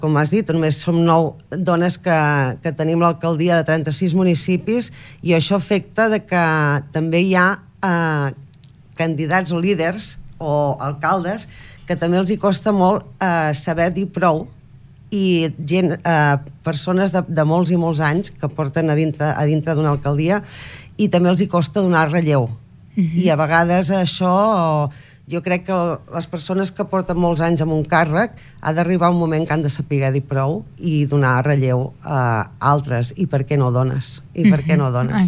Per això, ens ha visitat al Supermatí l’alcaldessa de Begur i alcaldable de Junts per Catalunya a Begur a les pròximes eleccions, Maite Selva.